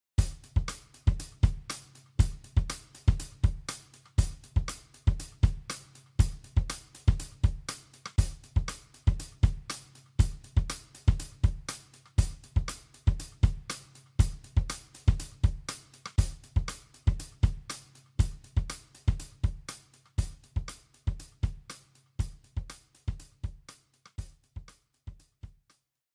The music that plays when loading on the title screen